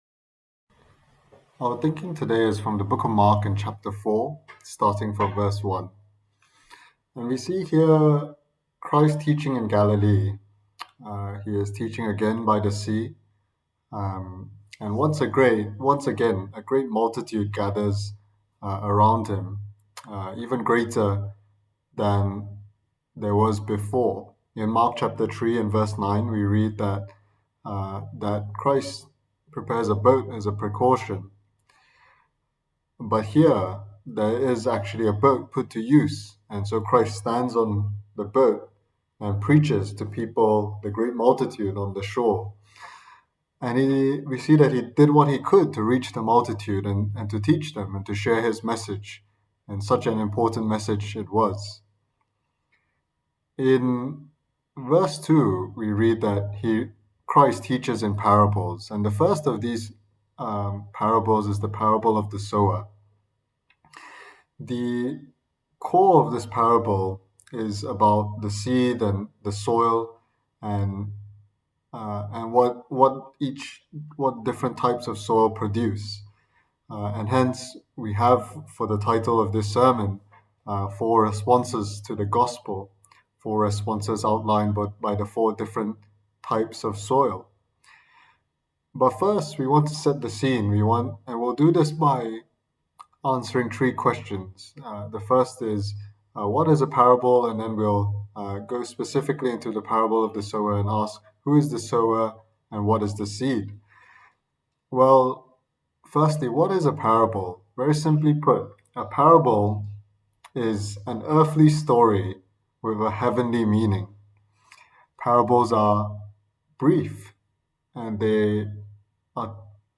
Preached on the 29th August 2021 on the gospel of Mark delivered online for the Sunday Evening Service